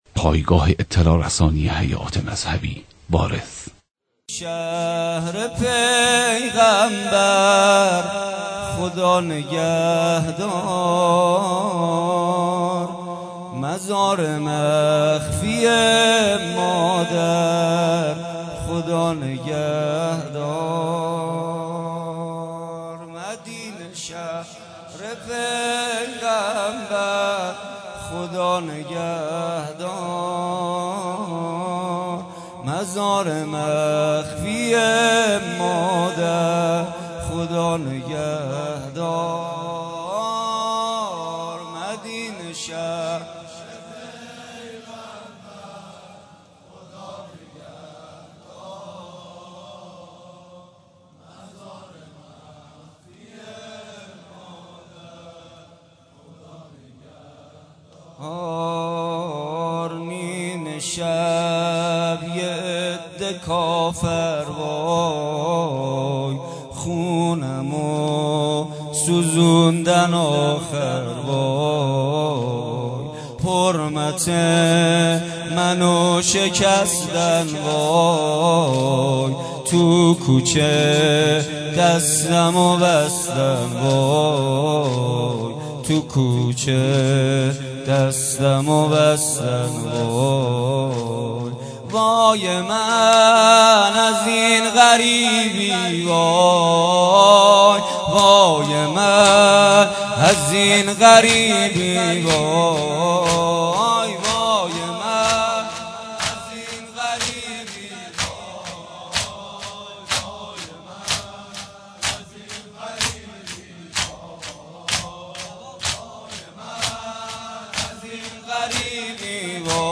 مداحی
به مناسبت شهادت امام صادق (ع)